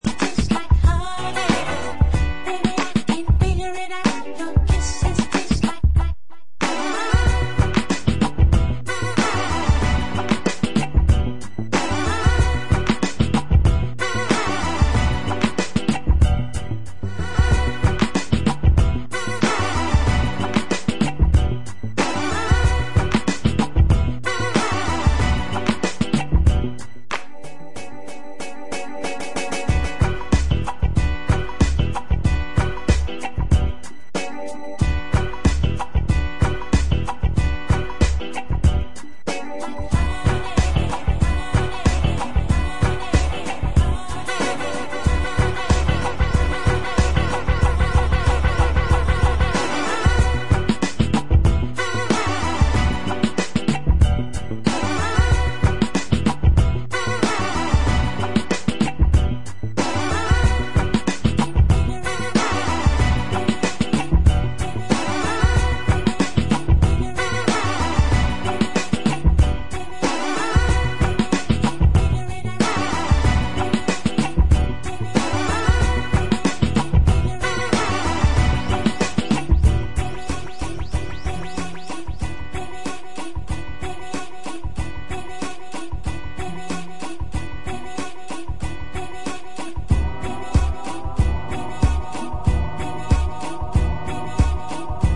a 3 tracker of deep disco explorations